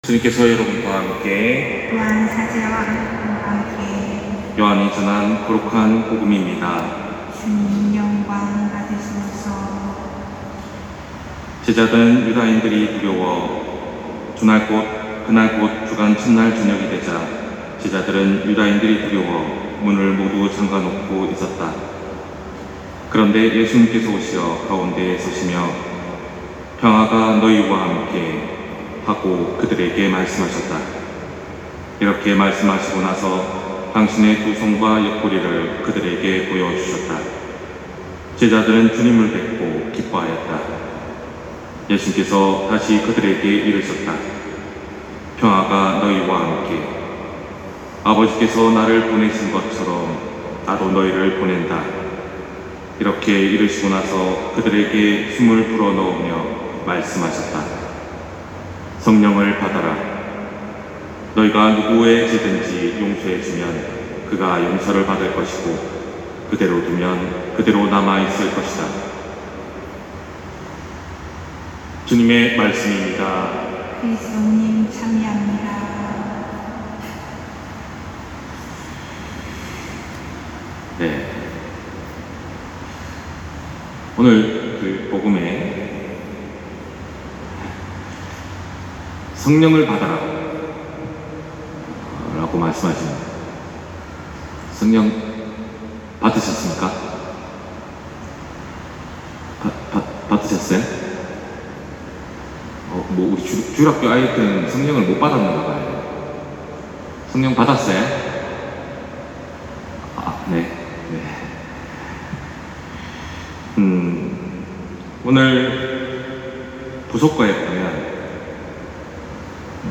250607 신부님 강론말씀